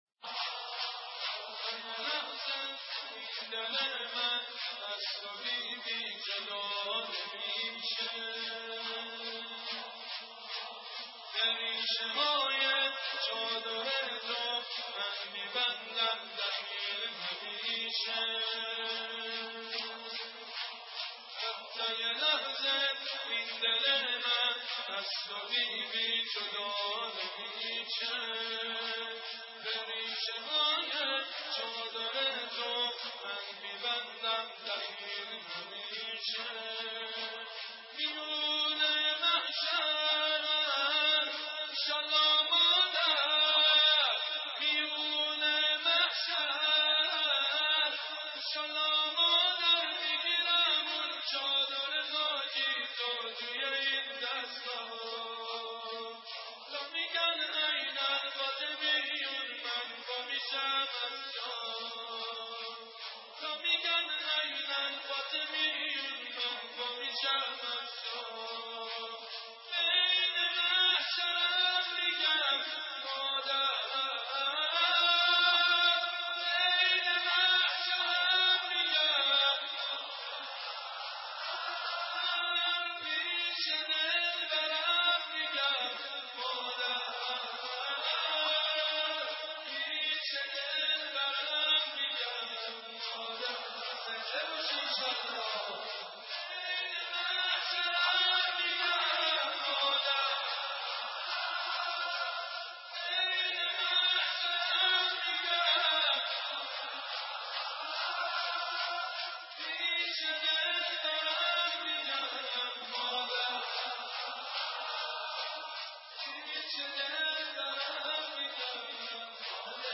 مراسم مداحی در ایام شهادت حضرت فاطمه زهرا(س)